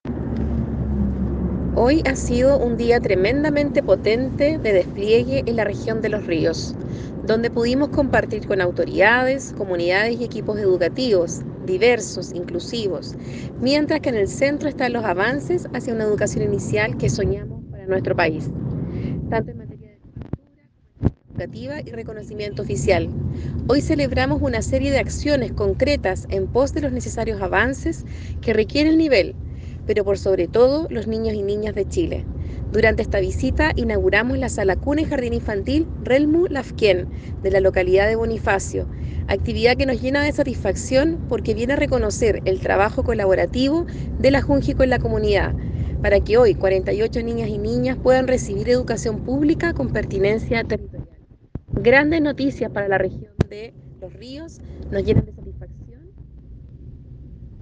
cuna-vicepres-junji-chile-daniela-trivino.mp3